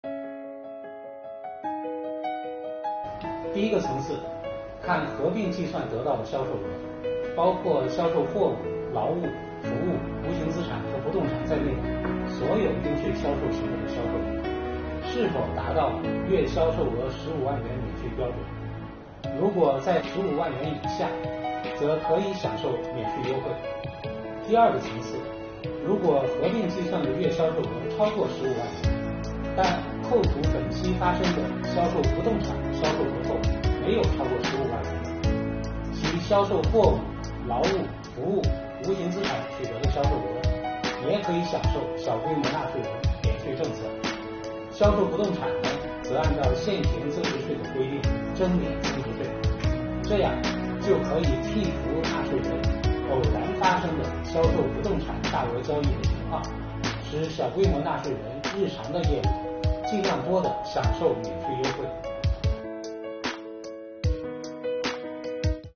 近日，国家税务总局推出最新一期“税务讲堂”课程，国家税务总局货物和劳务税司副司长吴晓强详细解读小规模纳税人免征增值税政策。今天我们学习：小规模纳税人月销售额是否达到15万元免税标准，判断依据的两个层次。